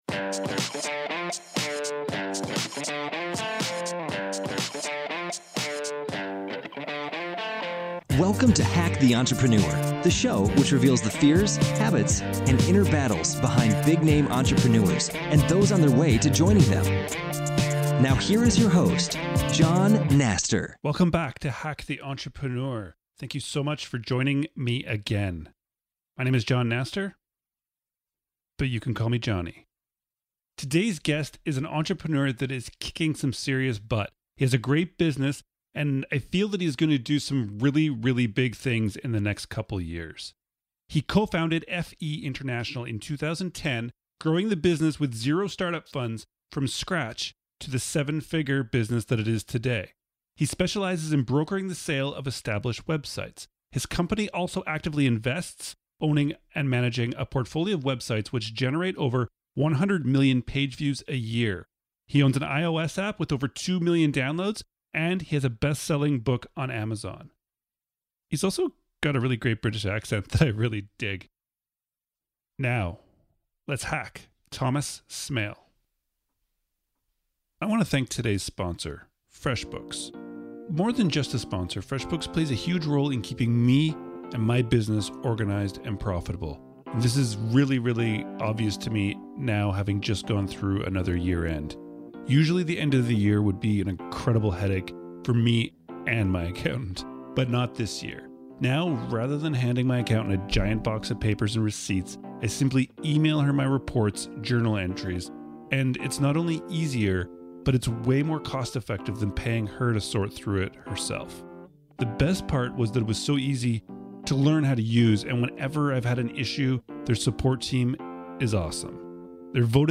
He’s also got a really great british accent that I really dig.